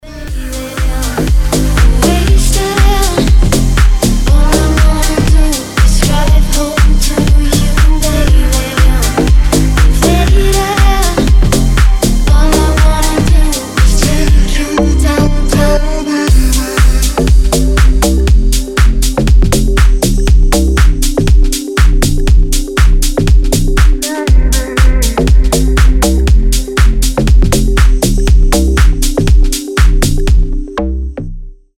громкие
deep house
Cover
чувственные
кайфовые